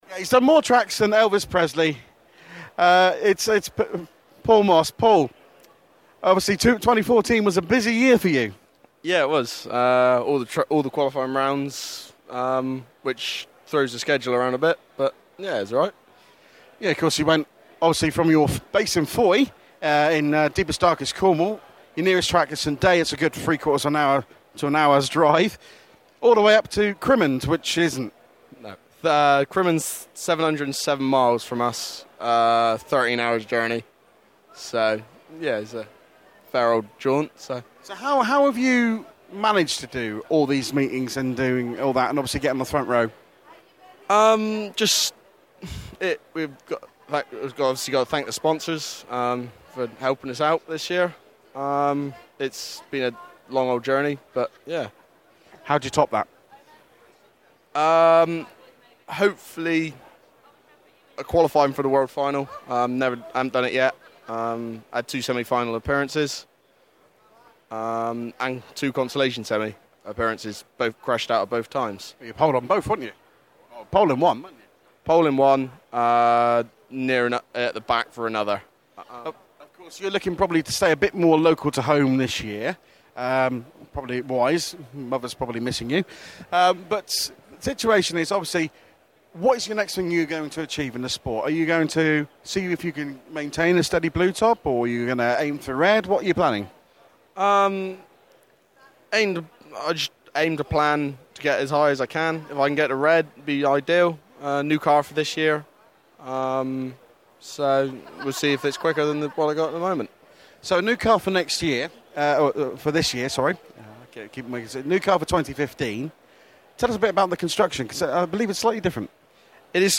Autosport Show - Interview